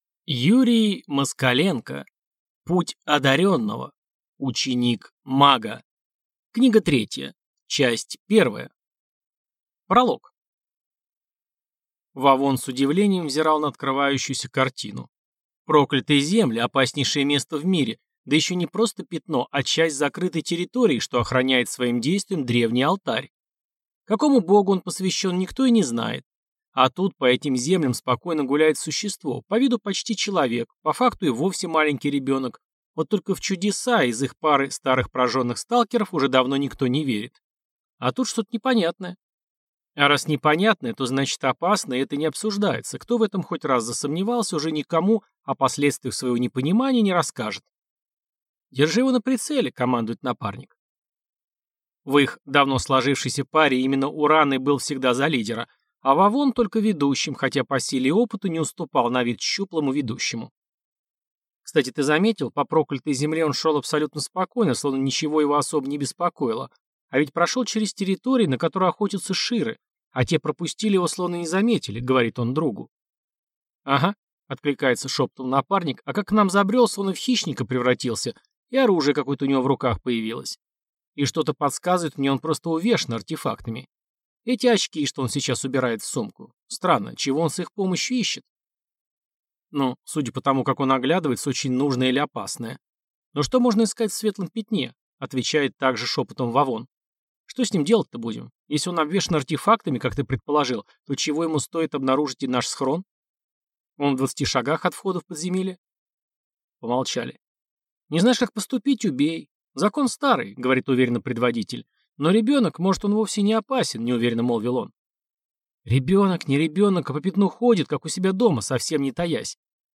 Аудиокнига Путь одарённого. Ученик мага. Книга третья. Часть первая | Библиотека аудиокниг